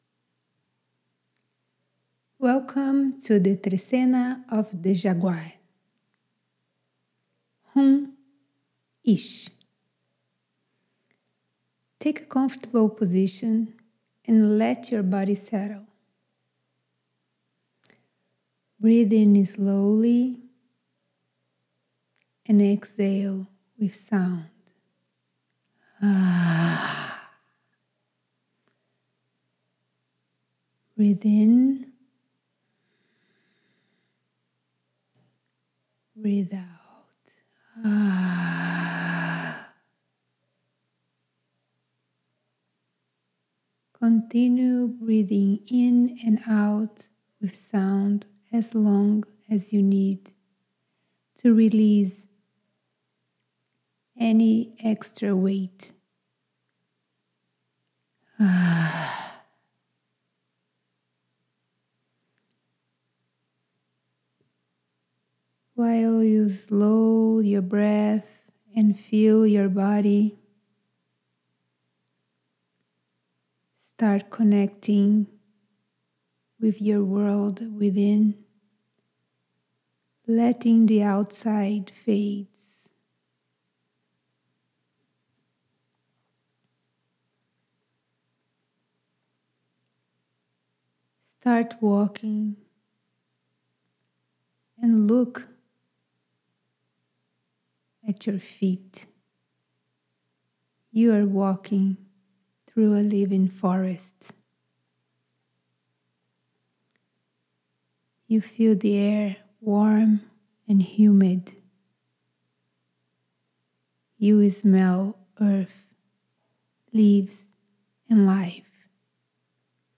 If possible, repeat the audio meditation of the Trecena to call in Jaguar Nawal: